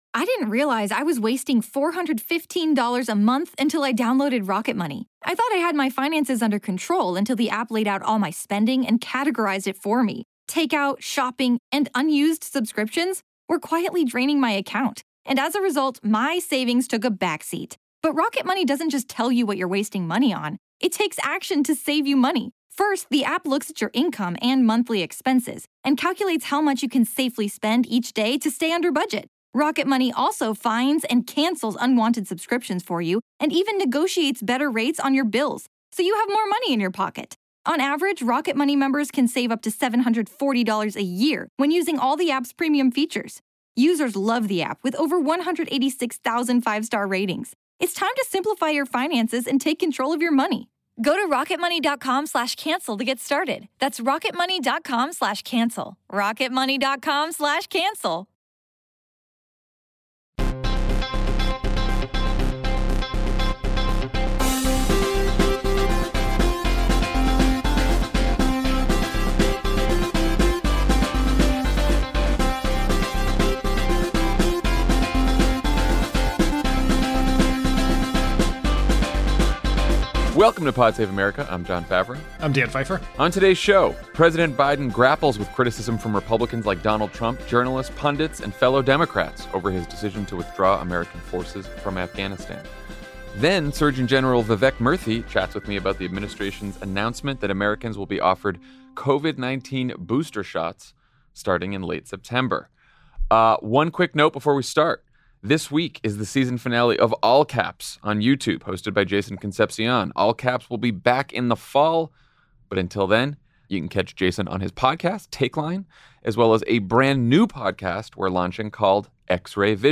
President Biden grapples with criticism from Republicans like Donald Trump, journalists, pundits, and fellow Democrats over the withdrawal of American forces from Afghanistan. Then, Surgeon General Vivek Murthy talks with Jon Favreau about the Administration’s announcement that Americans will be offered Covid-19 booster shots starting in late September.